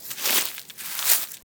dig_1.wav